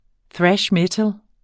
Udtale [ ˈθɹaɕ ˈmεtəl ]